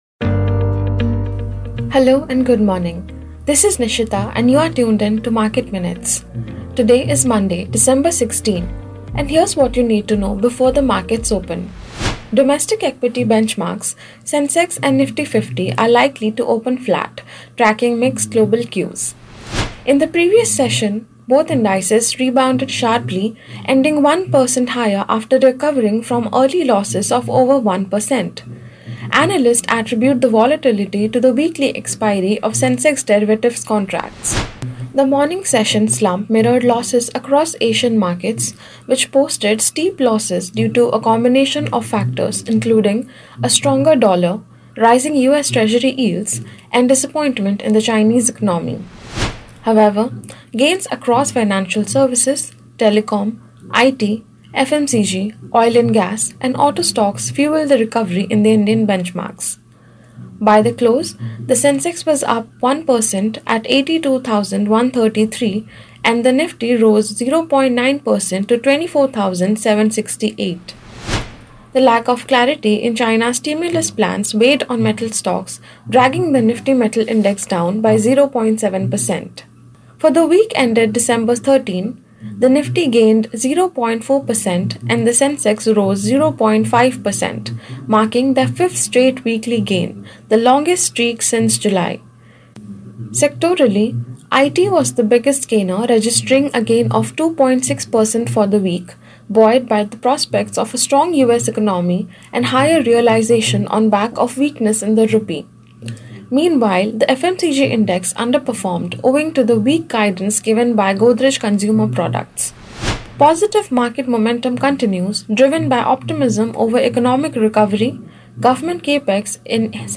Market Minutes is a morning podcast, putting the spotlight on trending stocks, crucial data points, and evolving market trends.